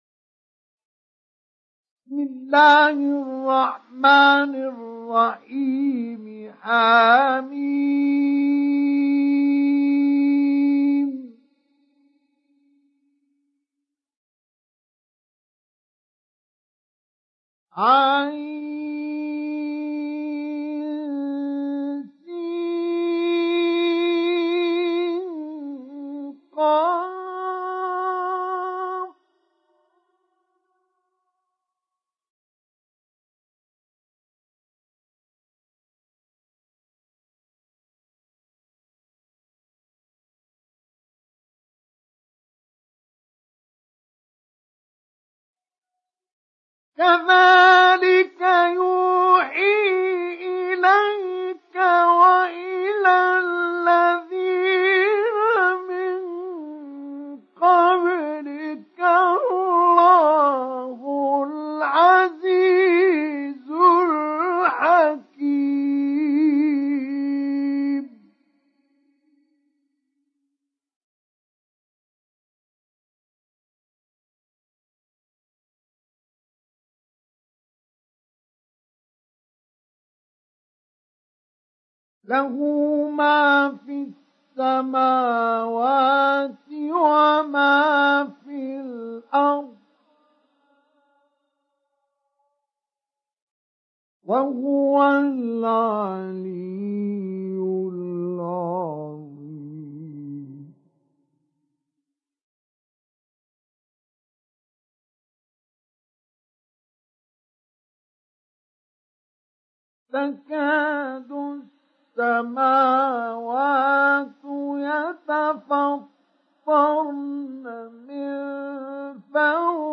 تحميل سورة الشورى mp3 بصوت مصطفى إسماعيل مجود برواية حفص عن عاصم, تحميل استماع القرآن الكريم على الجوال mp3 كاملا بروابط مباشرة وسريعة
تحميل سورة الشورى مصطفى إسماعيل مجود